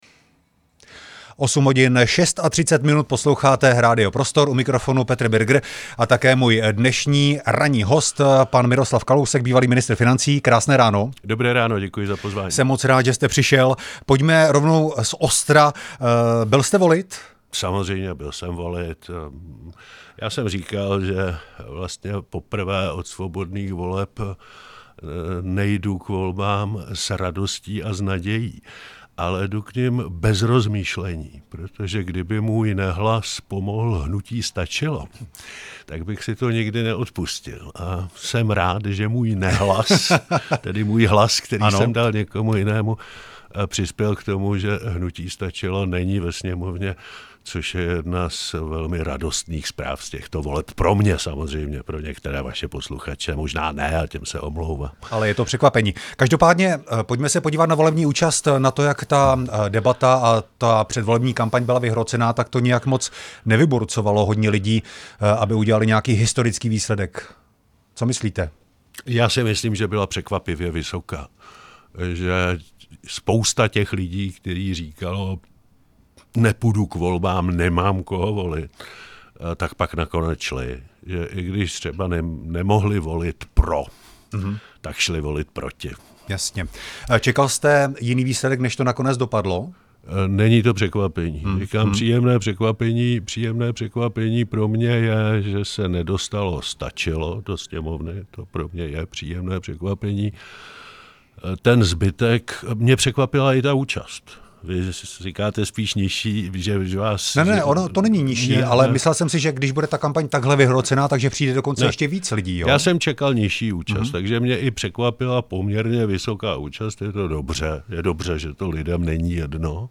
Rozhovor s exministrem financí Miroslavem Kalouskem